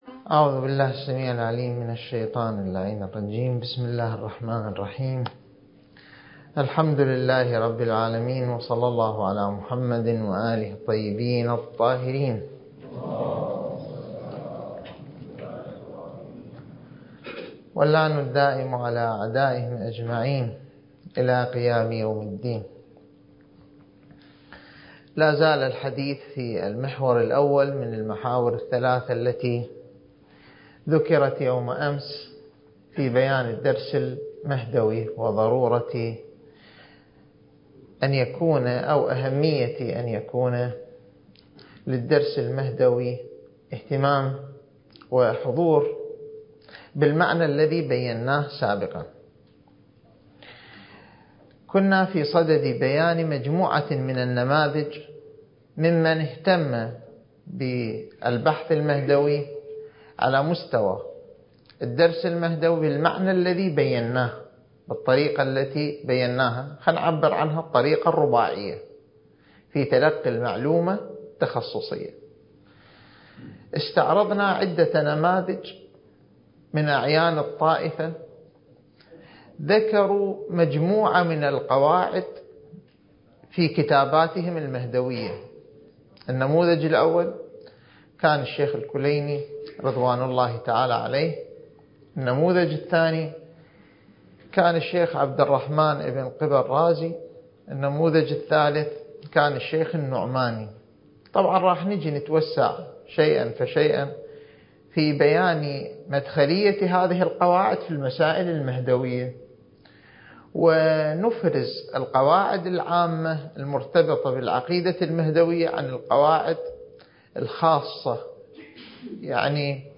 الدورة المهدوية الأولى المكثفة (المحاضرة السابعة والعشرون)